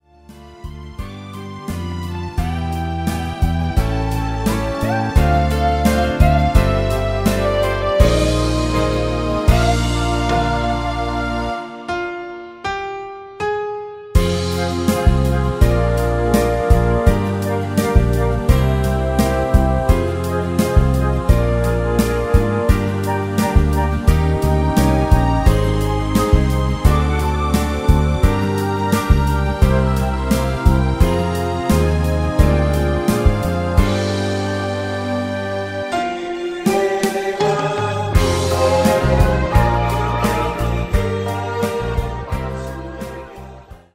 avec choeurs originaux